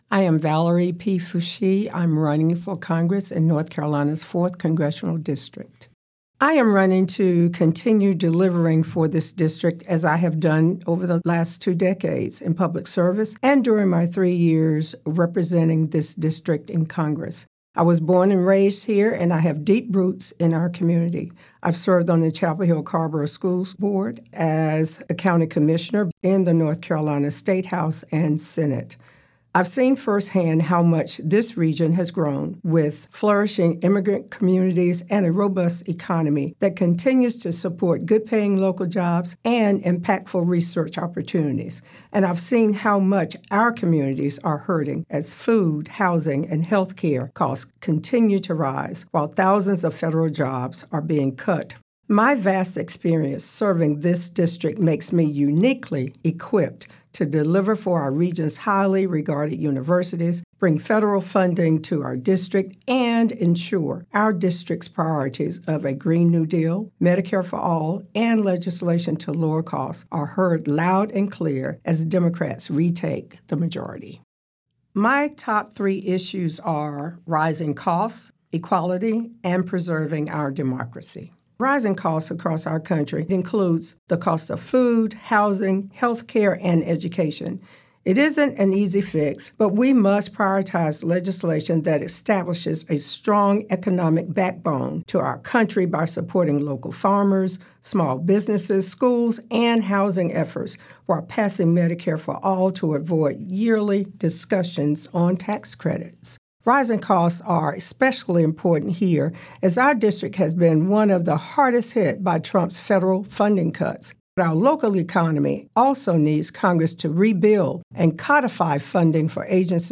Their answers (which are edited for clarity and brevity) are shared here, as well as links to their respective campaign websites or pages.
97.9 The Hill spoke with each candidate, asking these questions that are reflected in the recorded responses: